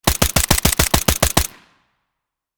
Download Free Guns Sound Effects | Gfx Sounds
M240B-machine-gun-automatic-fire-3.mp3